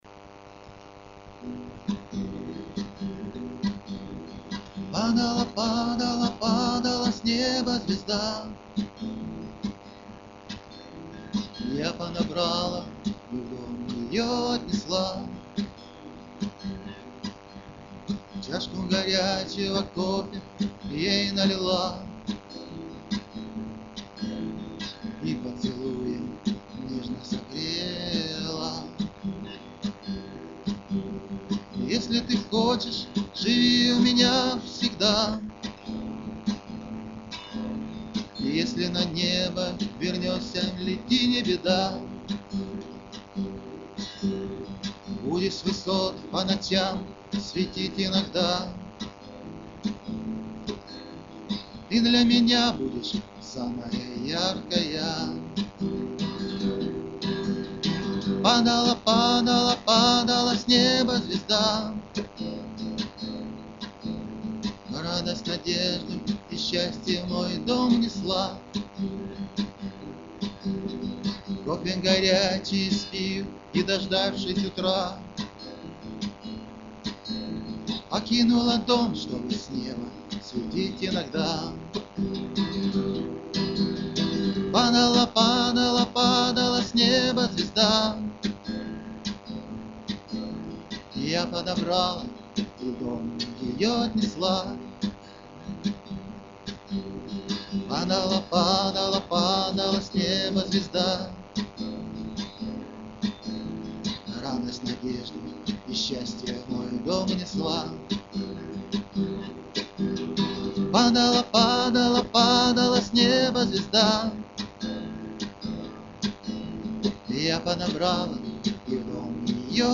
(авторское исполнение)
Рубрика: Поезія, Авторська пісня
Так некое извлечение аккордов.
Единственно, конечно, мешает не совершенное (приметивное) качество записи.